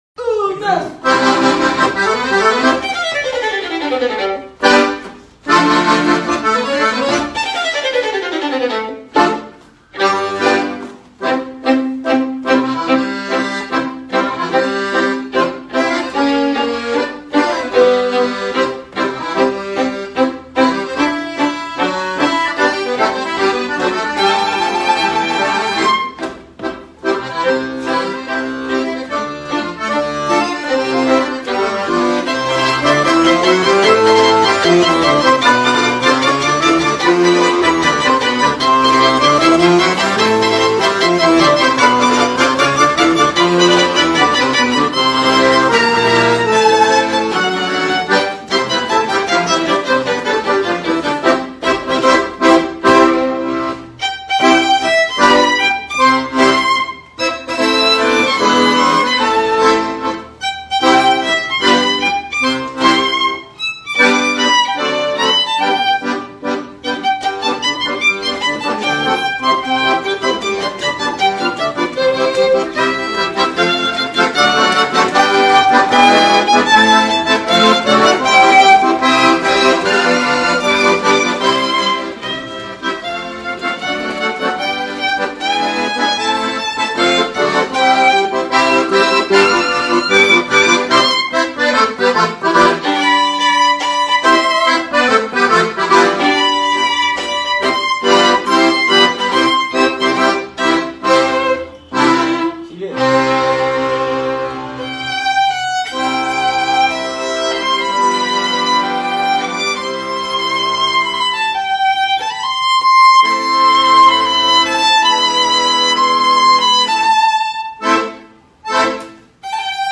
acordeon y violin